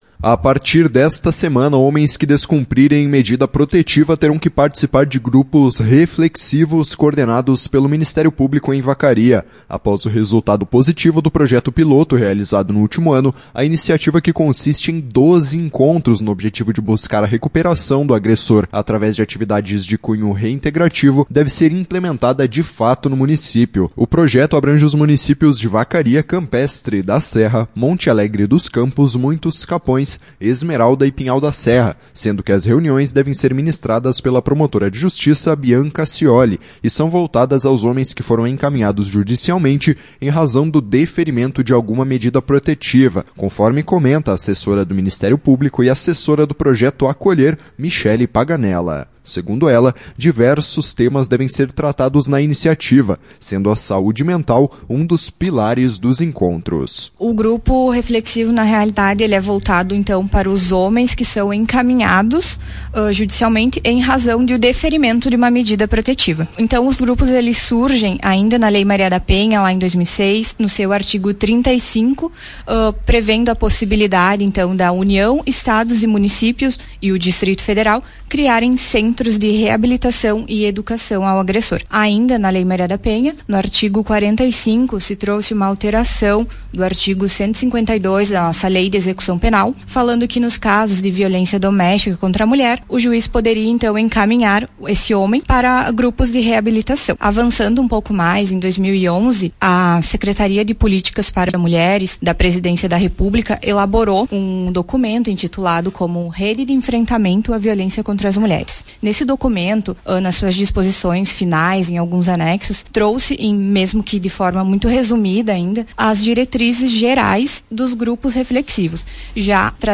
Em entrevista à Tua Rádio Fátima